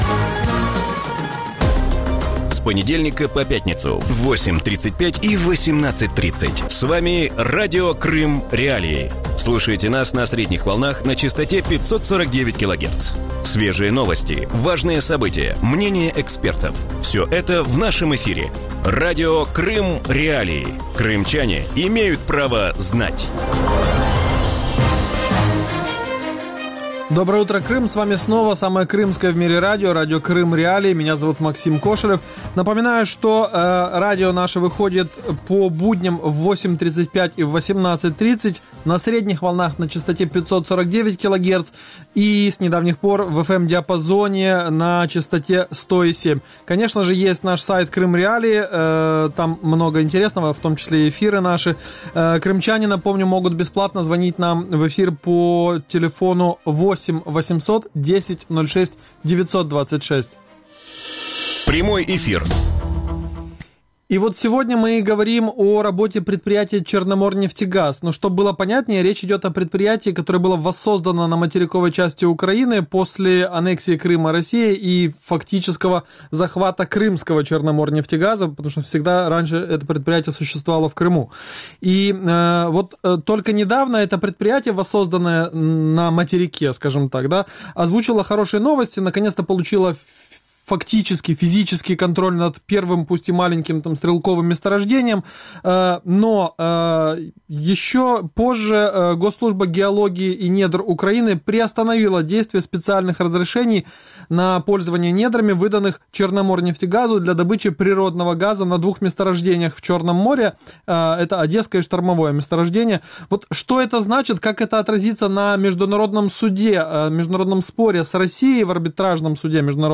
Утром в эфире Радио Крым.Реалии говорят о работе предприятия «Черноморнефтегаз» в Крыму. Госслужба геологии и недр Украины приостановила действие специальных разрешений на пользование недрами, предоставленных «Черноморнефтегазу» для добычи природного газа на Одесском и Штормовом месторождениях на шельфе Черного моря. Что это значит и как это отразится на международном судебном споре в арбитражном суде?